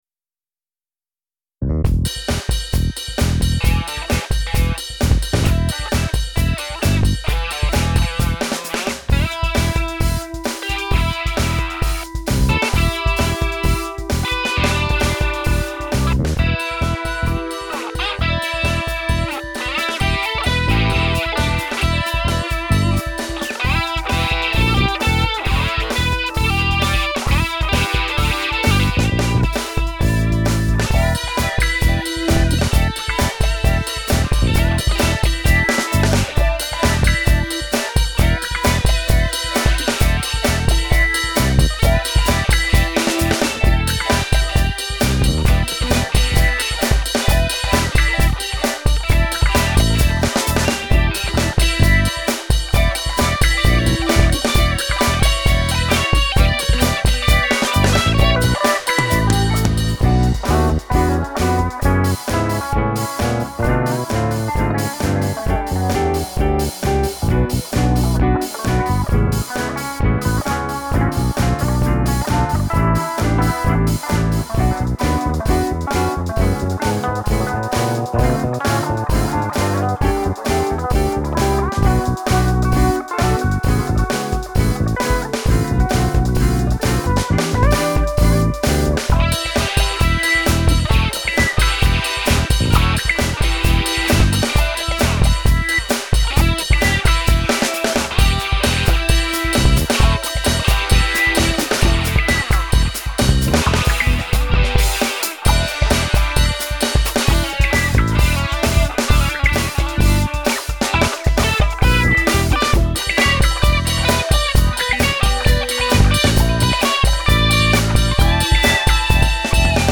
Die Solo-Gitarrenspuren sind mit dem Mittelfinger der rechten Hand gezupft, weil das zu dem Zeitpunkt, das einzige war, was ich am rechten Arm noch einigermassen kontrollieren konnte.